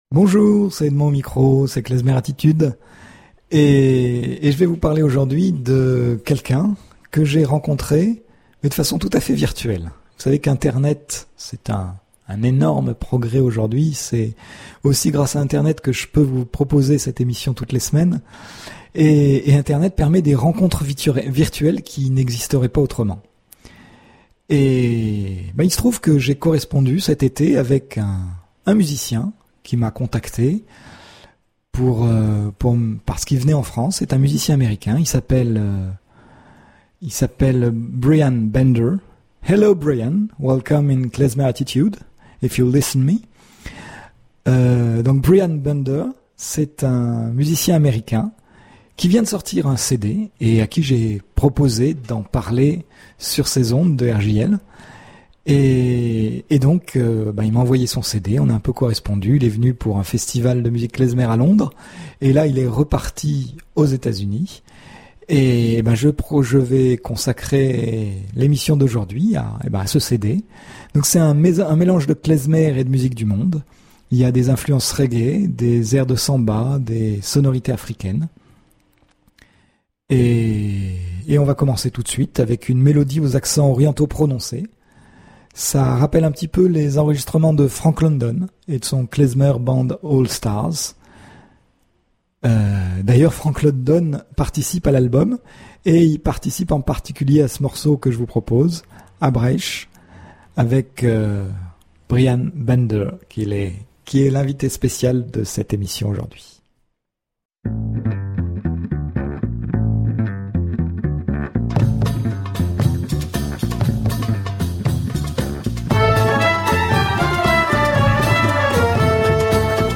30 minutes de musiques klezmer et de chansons yiddish, qui s’étend parfois vers d’autres horizons des musiques juives, telles les musiques sépharades, orientales, israéliennes, religieuses ou profanes.